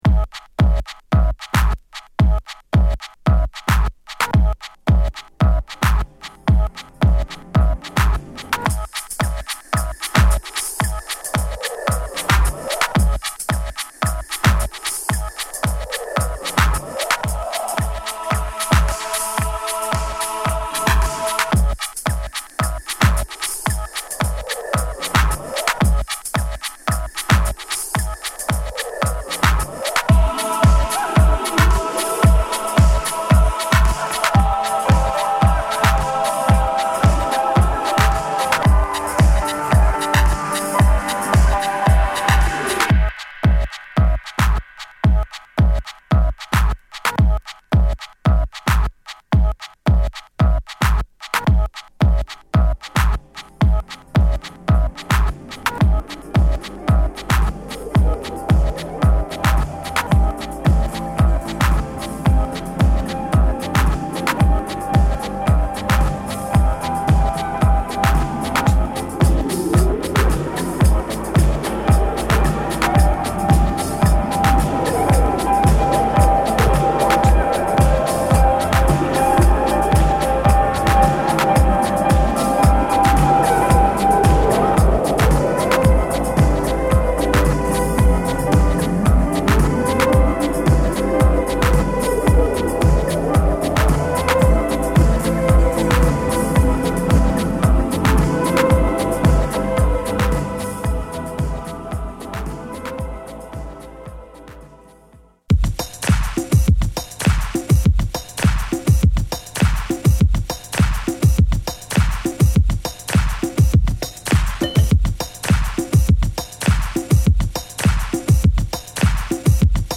しかしテンポはハウス・テンポ。